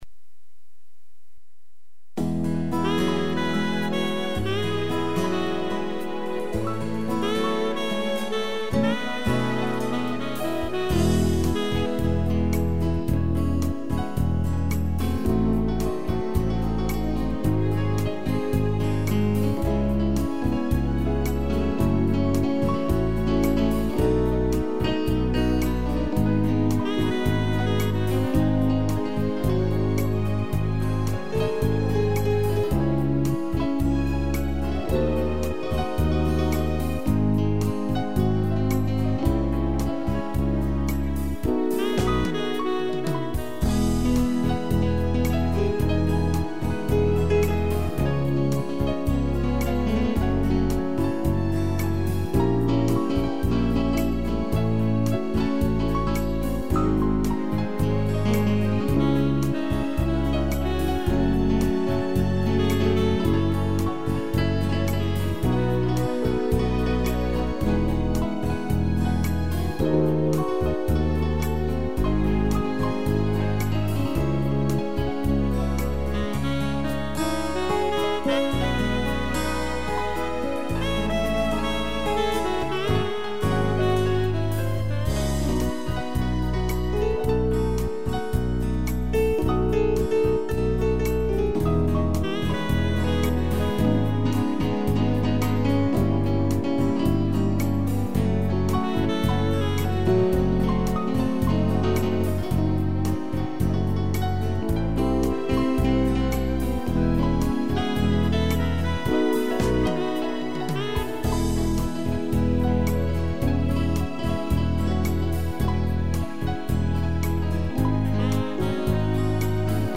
piano, sax e strings
(instrumental)